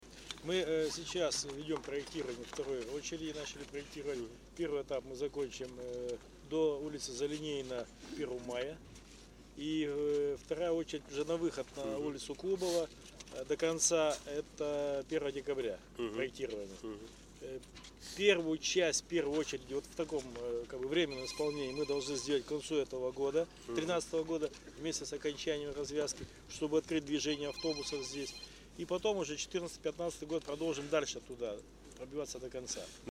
Евгений Шулепов рассказывает о строительстве виадука